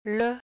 ə
le.mp3